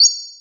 spark_bounce.ogg